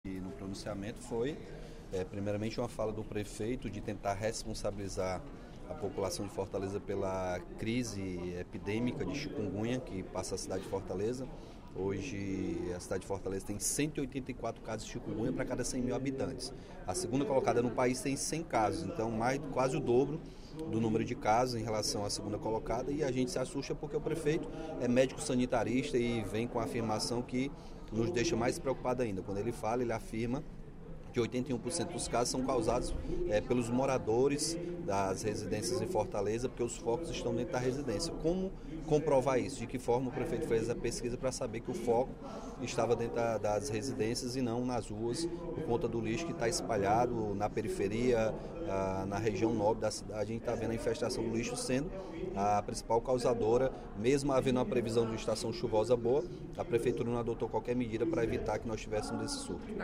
O deputado Capitão Wagner (PR) criticou, nesta quinta-feira (11/05), durante o primeiro expediente da sessão plenária da Assembleia Legislativa, a ausência de medidas, por parte da Prefeitura de Fortaleza, para conter o aumento de casos de doença na cidade.